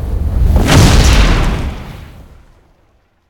dragonlanding.ogg